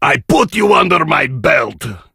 grom_lead_vo_02.ogg